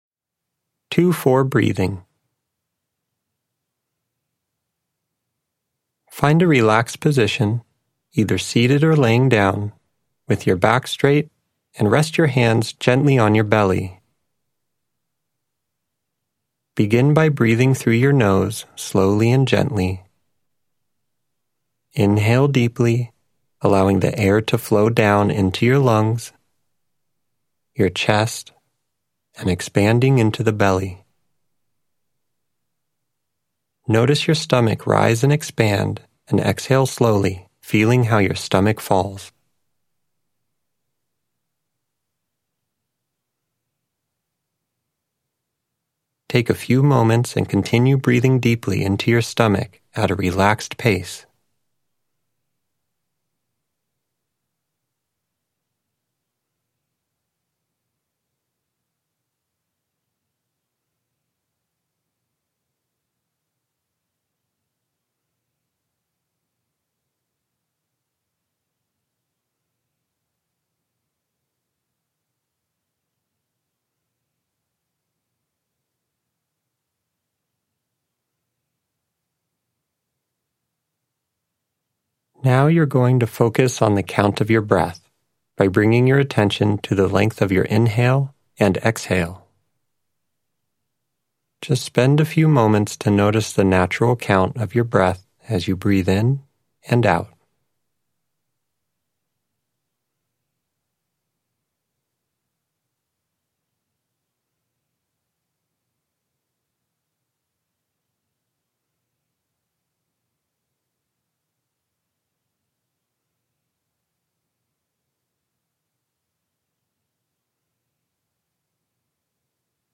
Guided Meditations & Mindfulness